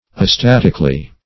astatically - definition of astatically - synonyms, pronunciation, spelling from Free Dictionary Search Result for " astatically" : The Collaborative International Dictionary of English v.0.48: Astatically \A*stat"ic*al*ly\, adv. In an astatic manner.
astatically.mp3